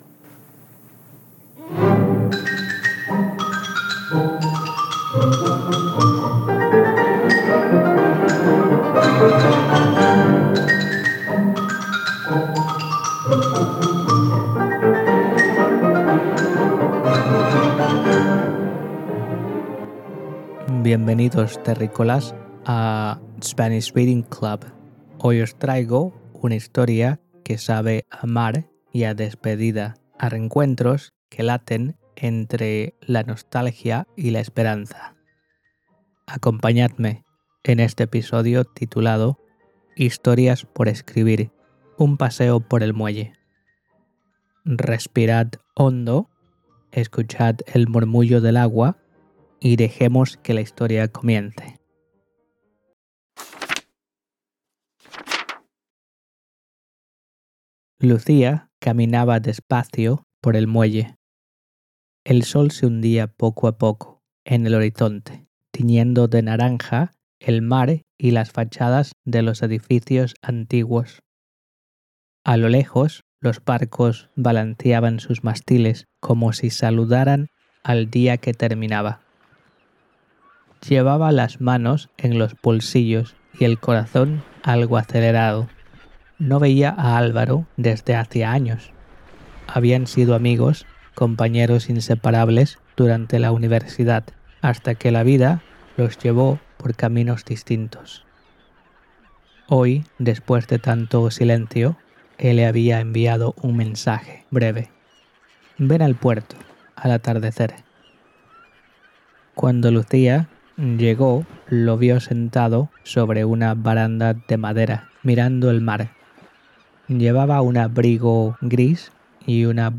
• Sound effects: sourced from Mixkit